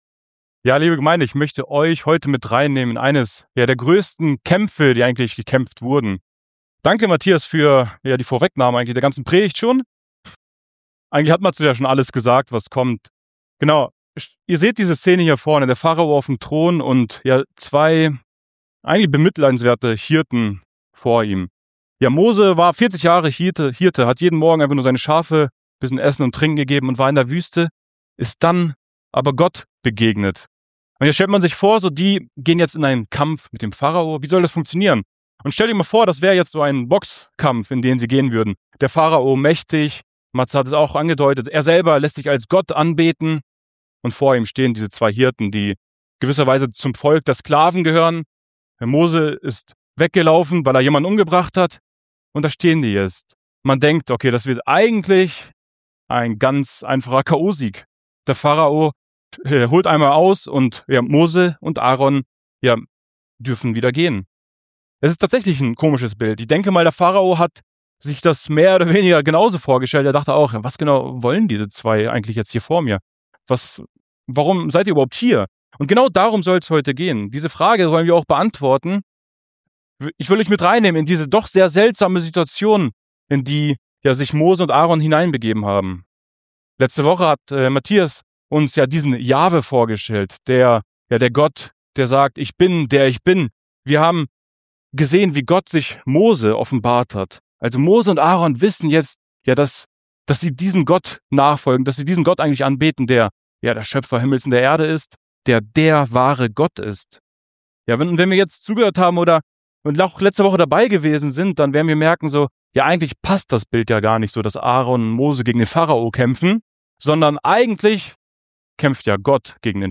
Predigtreihe Exodus - Part 3 Predigt